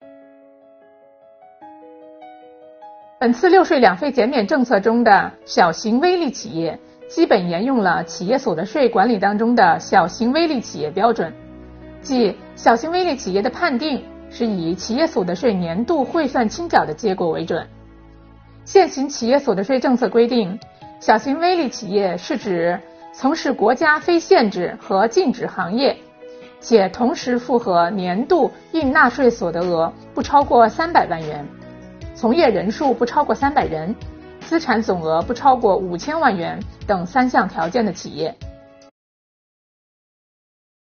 近日，国家税务总局推出新一批“税务讲堂”系列课程，为纳税人缴费人集中解读实施新的组合式税费支持政策。本期课程由国家税务总局财产和行为税司副司长刘宜担任主讲人，解读小微企业“六税两费”减免政策。